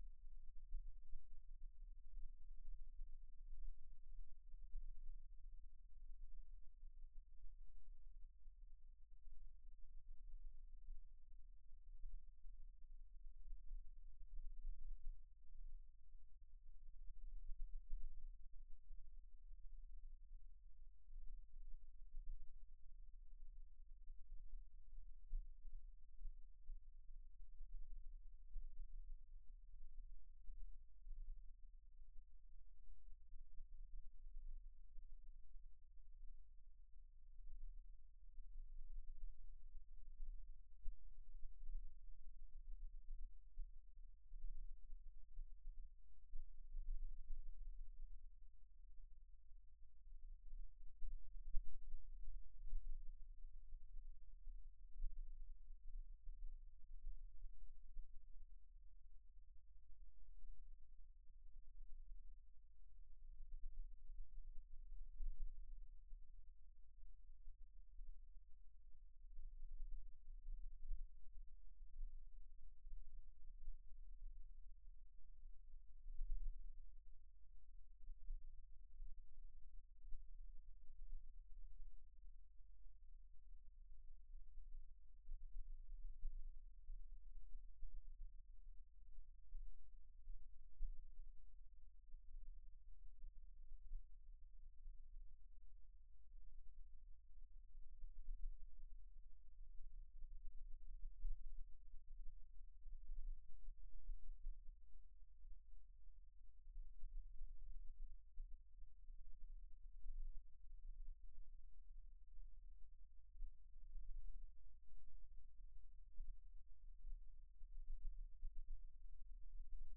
Station 04 - ROMAGNA OBSERV. From Sogliano al Rubicone(FC), N Italy. Induction coil reception (on top) compared with borehole sensor (bottom). PC1 and its structures are clearly visible.
Borehole sensor seems to capture only local noise, probably correlated with main power supply ground connections.